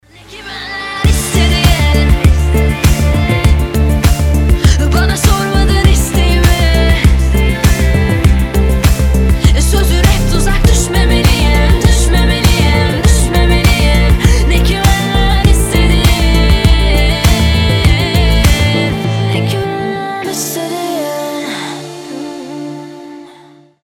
красивый женский голос